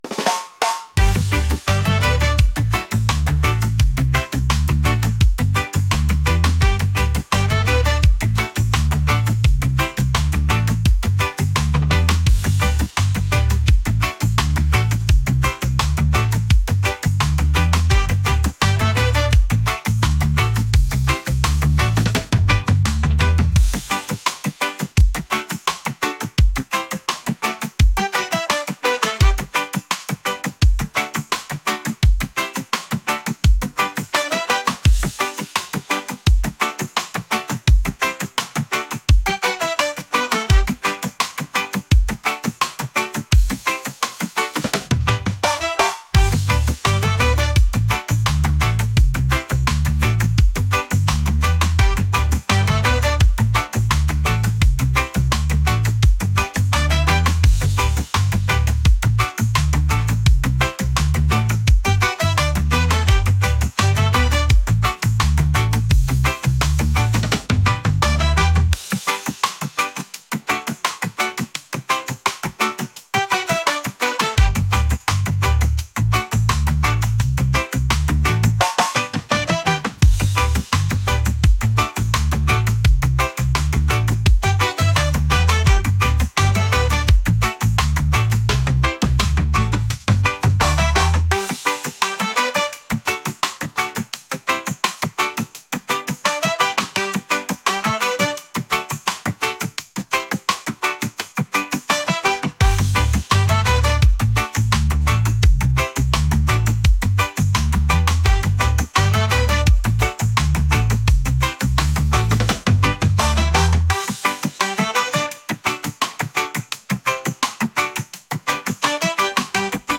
reggae | pop | soul & rnb